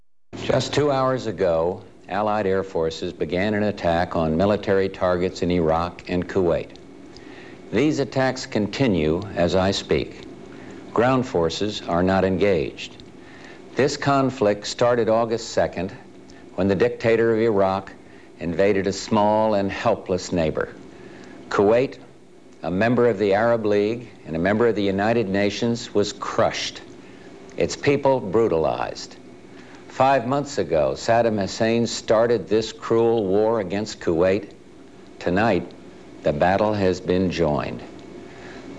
(1.6Mo)1991Discours de Georges BUSH justifiant les bombardements sur l'Irak.